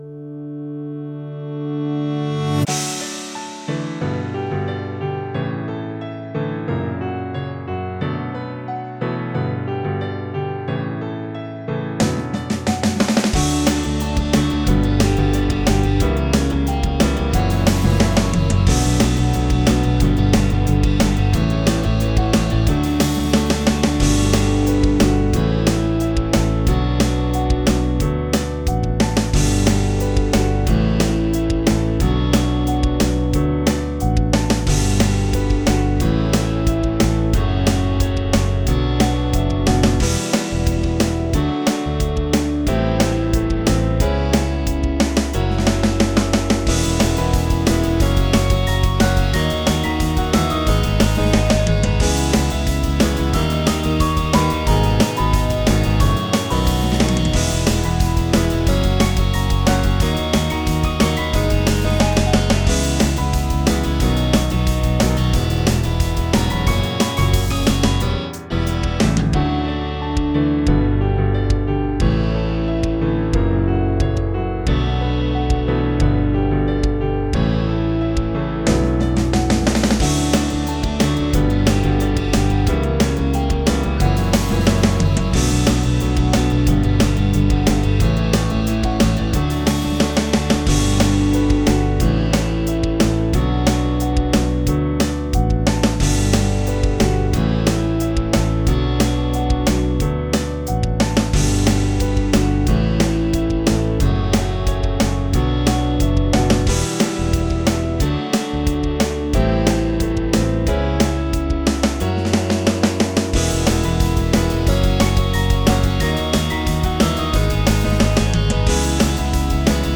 インスト音源 / コード譜 配布
今回はピアノをメインとして、ギター、ベース、ドラムを交えたシンプルなバンド編成で制作させていただきました。